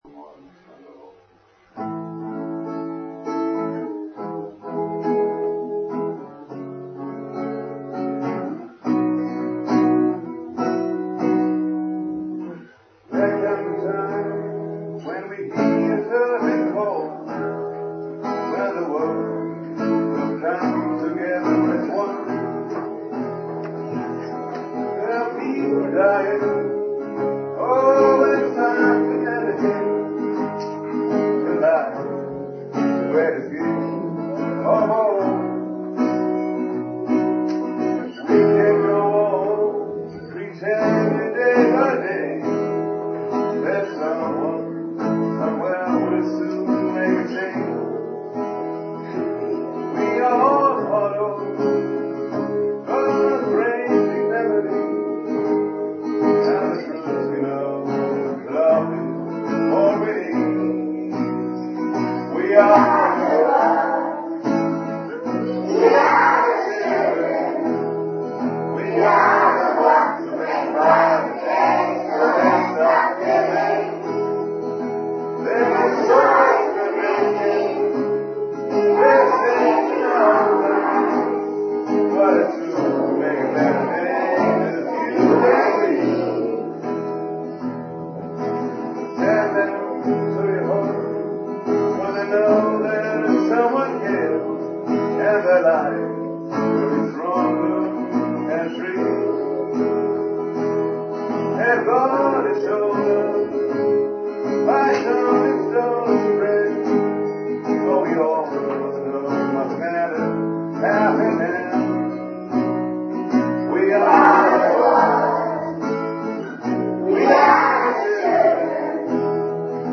Gekürzte Version
mit den Kindern der 4. Klassen